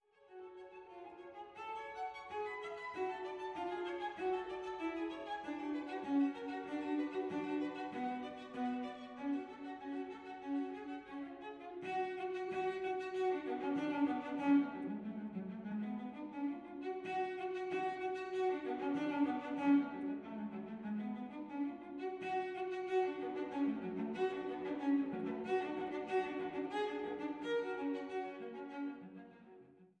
Violoncello